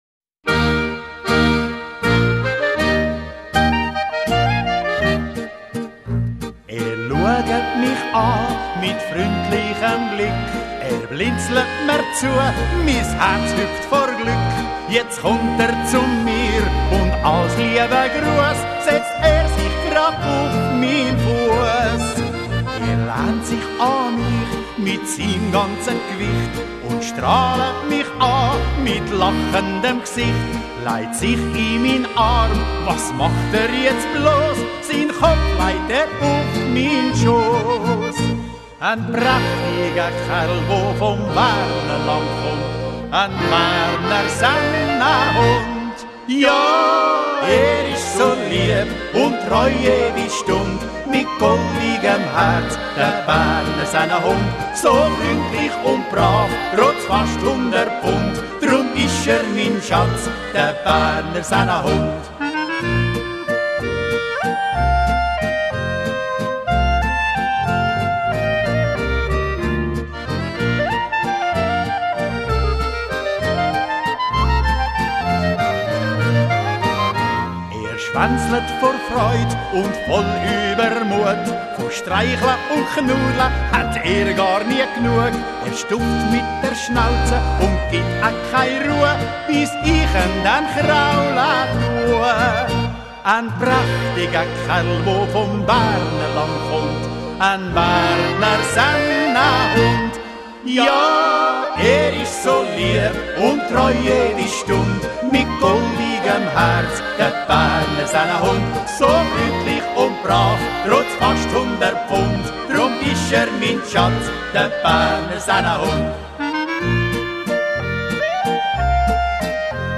der Schweizer Volksmusiker